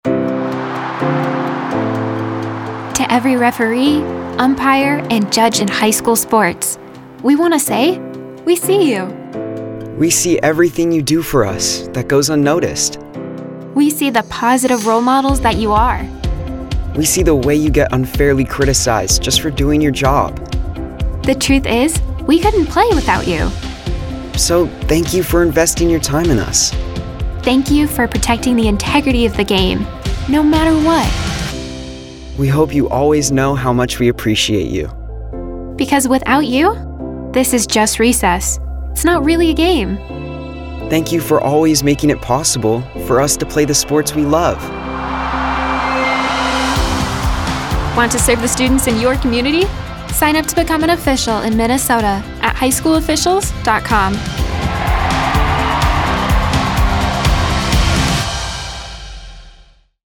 Public Service Announcements